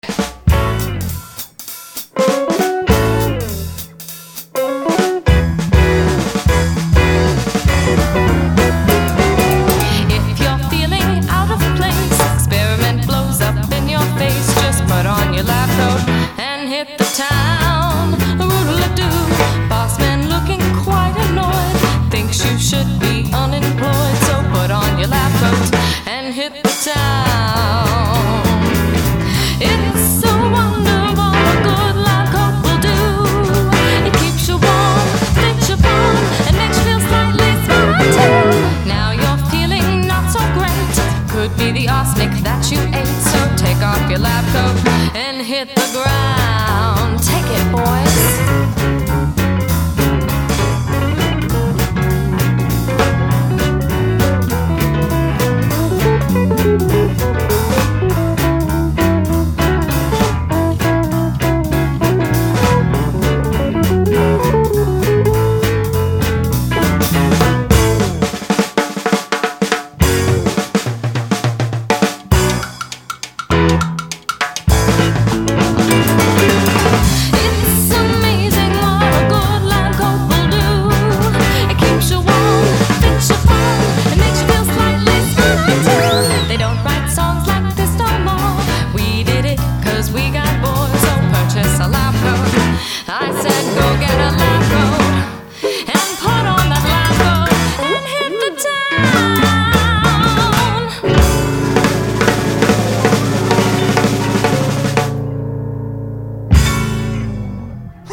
as its 1920's style perfectly suited her old-school vibe.
Drums
Guitars
Bass
Piano
Lead vocal